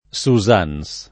[ S u @# n S ]